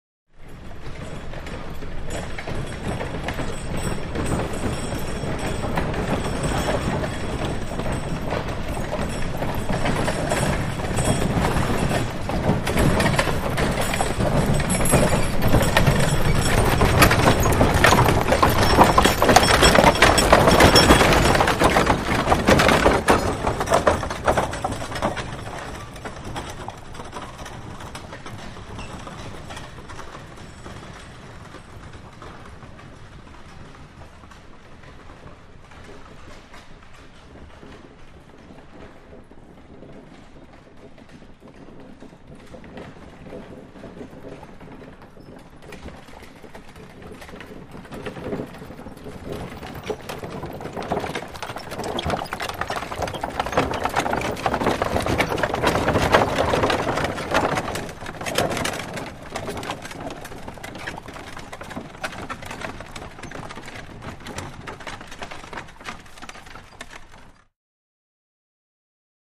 2 Wagons; By Slow, 2nd Wagon Stops, Mud And Stone Surface, Good Chain Rattles On Wagon 1, R-l